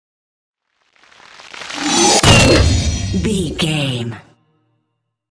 Dramatic horror metal hit large
Sound Effects
Atonal
heavy
intense
dark
aggressive